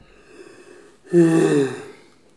baillement_02.wav